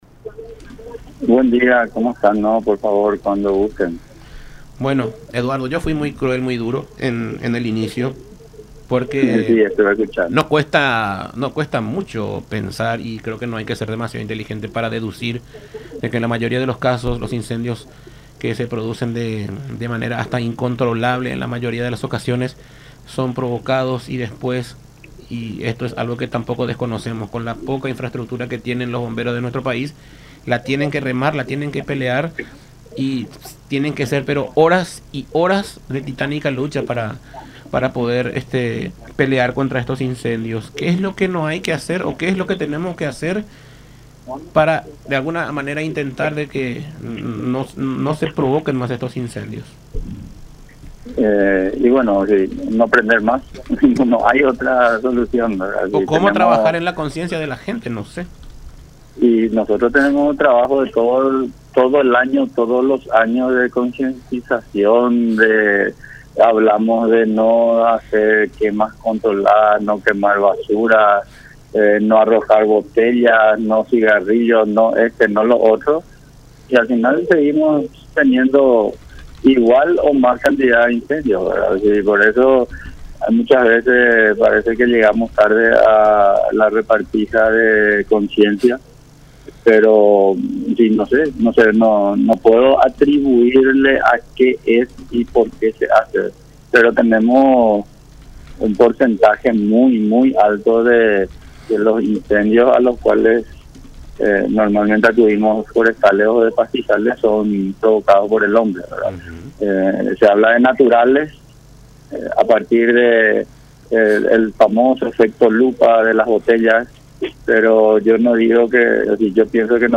en diálogo con Todas Las Voces por La Unión